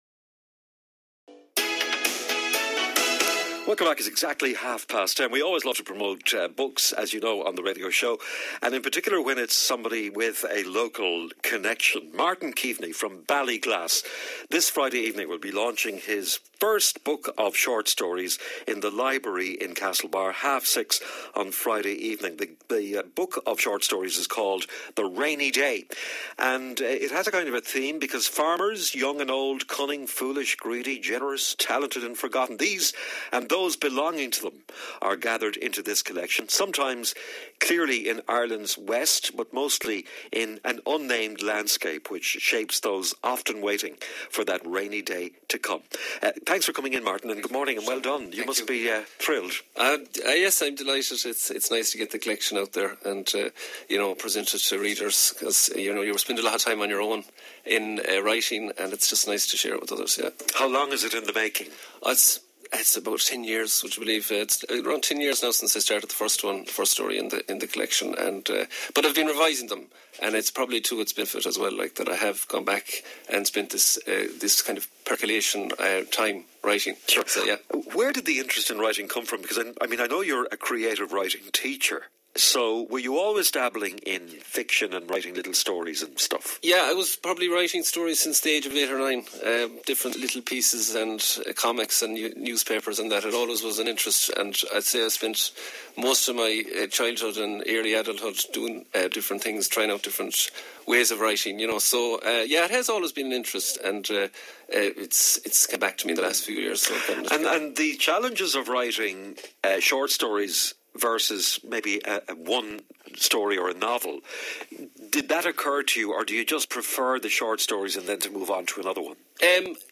MWR Interview